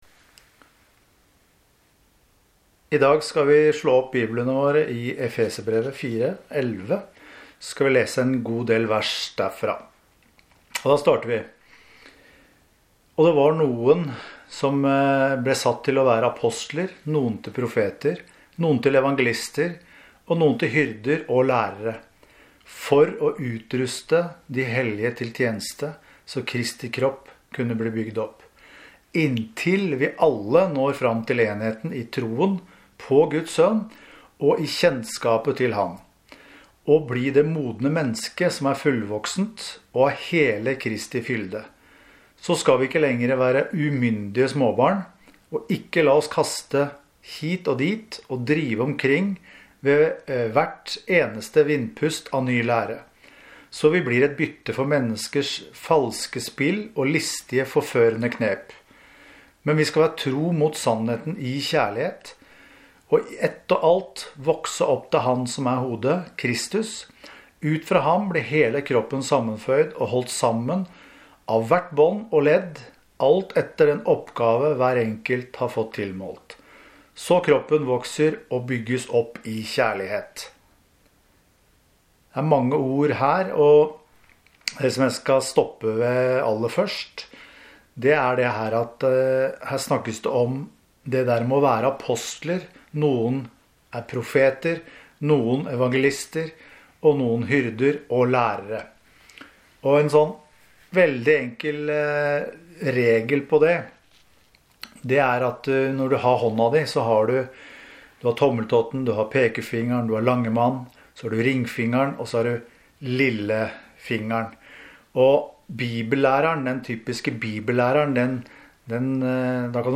Gudstjenester